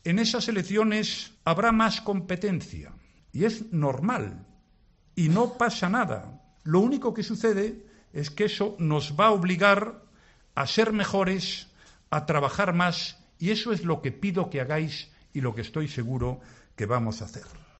El líder del PP ha intervenido ante la Junta Directiva Nacional, el máximo órgano entre congresos del partido, con un discurso en el que ha reconocido que su partido ha podido cometer errores en Cataluña, pero ha defendido la actuación del Gobierno ante el desafío independentista, que ha sido "buena para España aunque no lo haya sido para el PP".